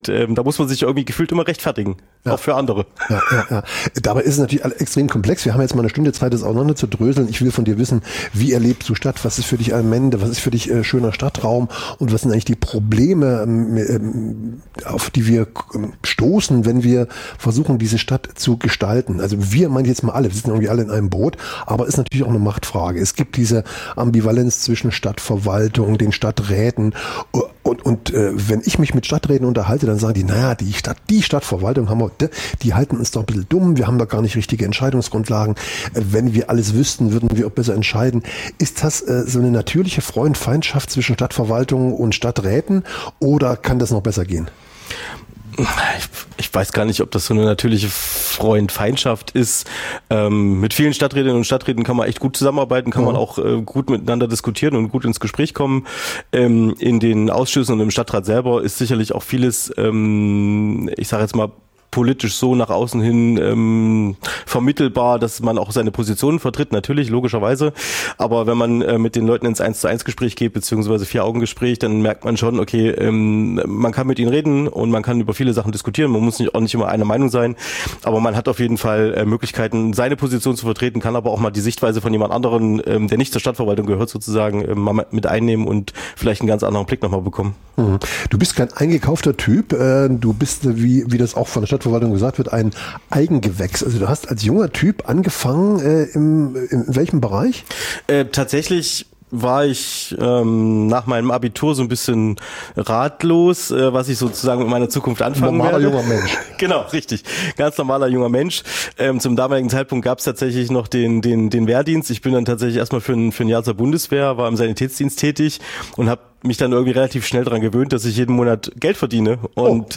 Das tagesaktuelle Livemagazin sendet ab 2024 montags bis freitags 9-11 Uhr. Jeden Tag von anderen Moderator:innen und thematisch abwechslungsreich best�ckt.